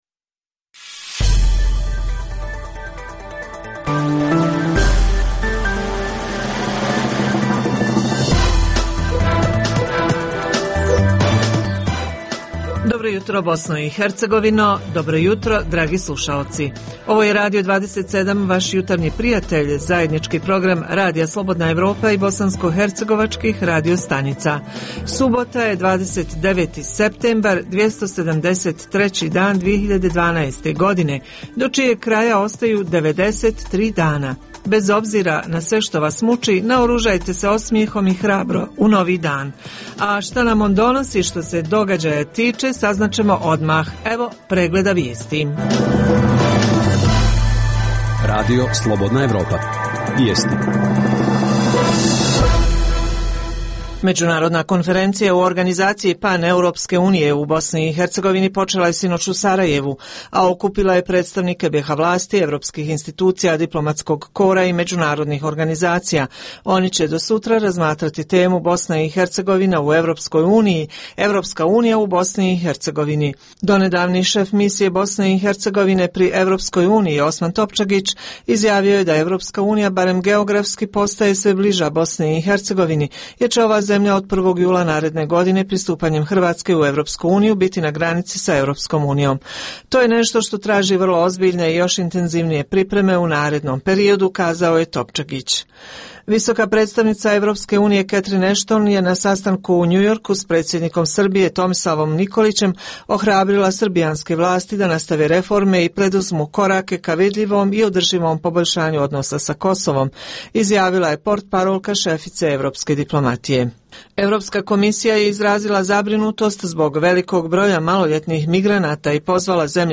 U Jutarnjem programu možete čuti: - Javljanja reportera iz Bijeljine i Višegrada o događajima u njihovoj sredini. - Reporter iz Brčkog informiše o tome, kako je u tom gradu obilježen Međunarodni dan turizma. - Radio Balkan – Banjaluka pripremio rubriku o mladim volonterima a Nezavisni radio Slon Tuzla, redovnu subotnju „Radio ordinaciju“ . - Tu su i druge „relaksirajuće" vikend teme, muzika i tri pregleda vijesti.